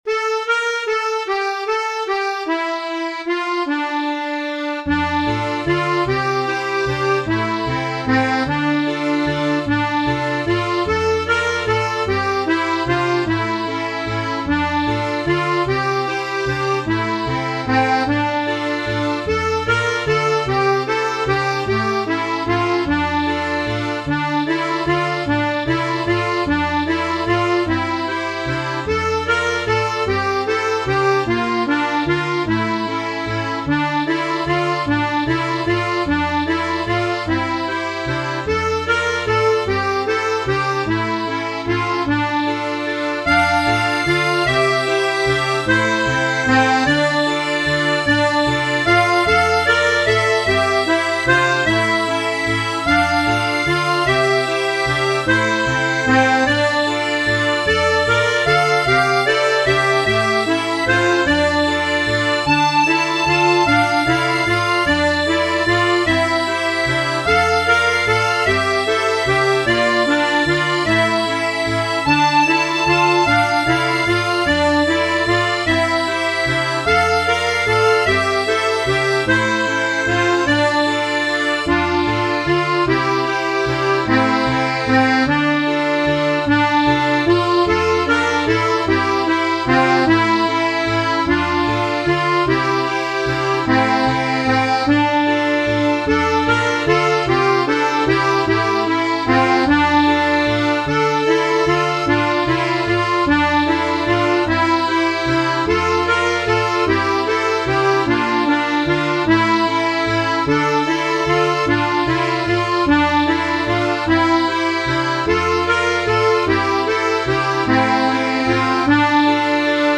R44 Valse de ferrieres R44 (Dm 2st hl )